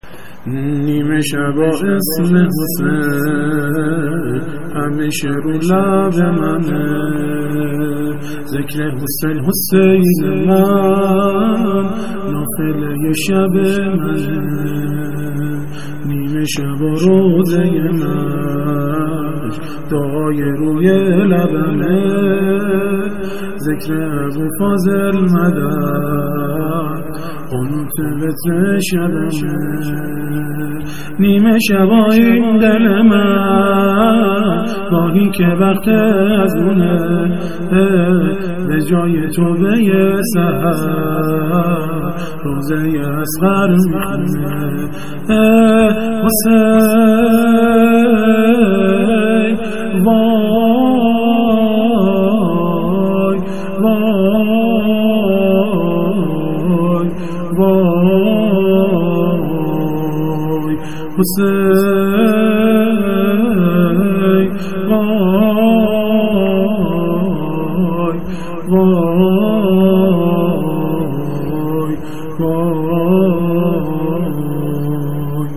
مناجات با امام حسین با زبان ساده -( نیمه شبا اسم حسین، همیشه رو لب منه )